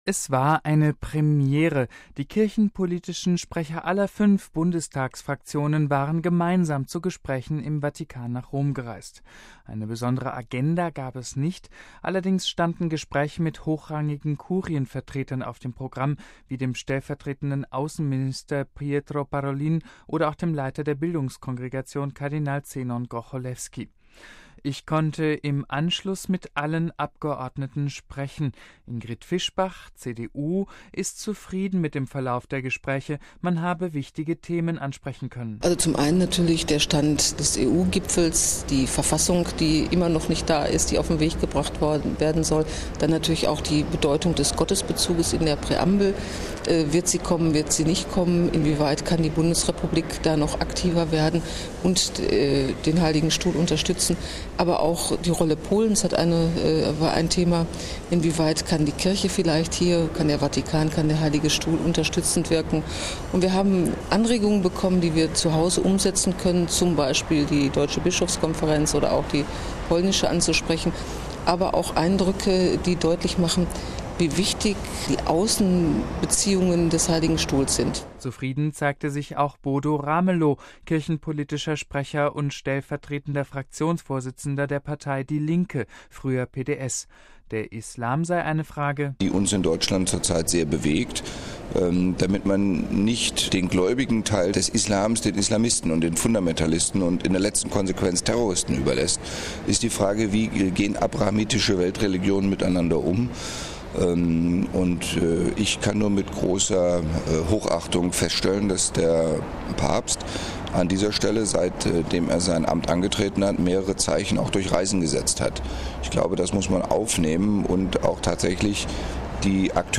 Eine besondere Agenda gab es nicht, allerdings standen Gespräche mit hochrangigen Kurienvertretern auf dem Programm wie dem stellvertretenden Außenminister Pietro Parolin oder auch dem Leiter der Bildungskongregation Kardinal Zenon Grocholewski. Ich konnte im Anschluss mit den allen Abgeordneten sprechen.